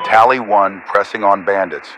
Radio-pilotWingmanEngageAir2.ogg